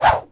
FootSwing5.wav